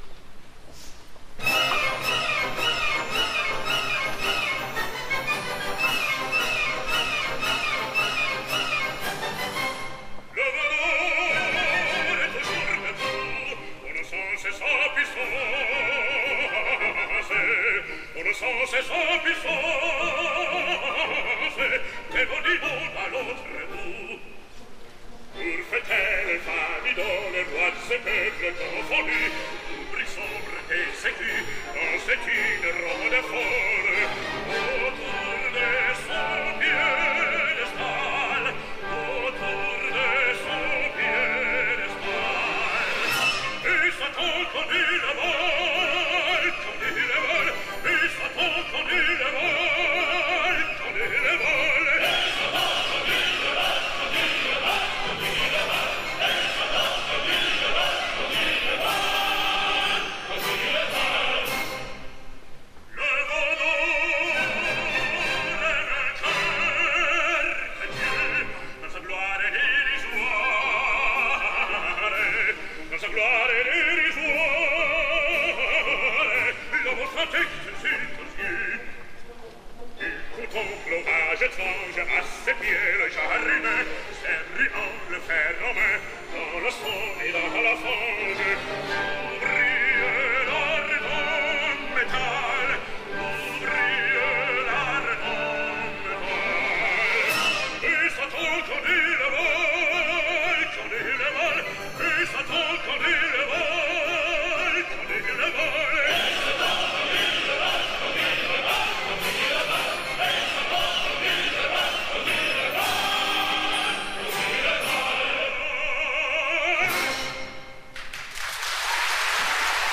El 11 d’octubre passat s’estrenava a la Wiener Staatsoper una nova producció del Faust de Charles Gounod.
Méphistophélès: Kwangchul Youn
Tots els fragments son del dia de l’estrena, 11 d’octubre de 2008.
Escoltem com Kwangchul Youn canta “Le veau d’or”